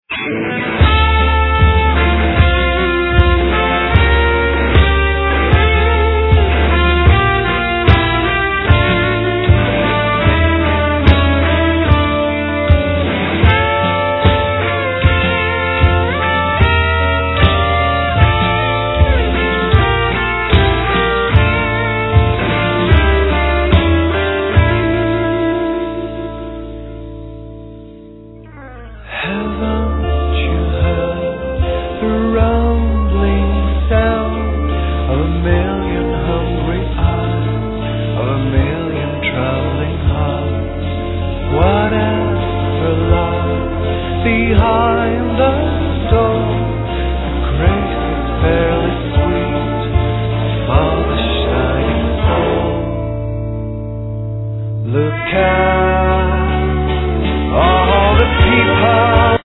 (Lead Vocals, Trumpet, Flugelhorn)
(Drums, Backing Vocals)